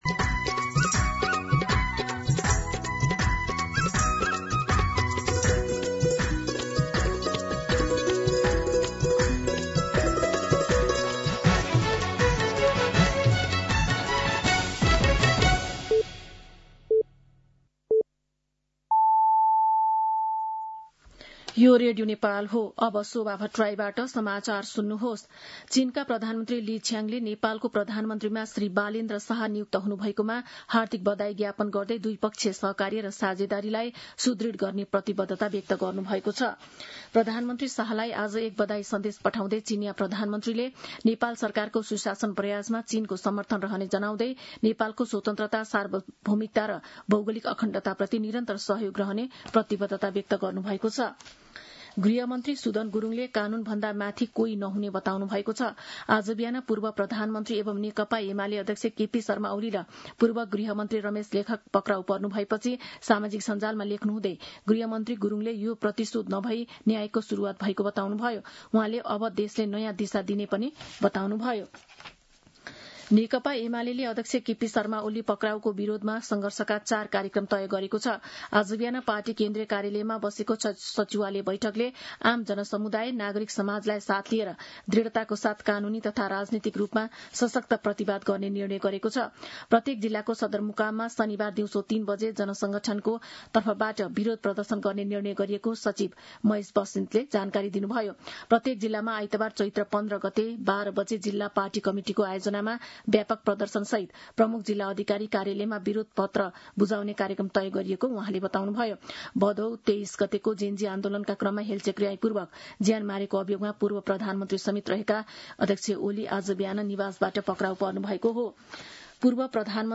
मध्यान्ह १२ बजेको नेपाली समाचार : १४ चैत , २०८२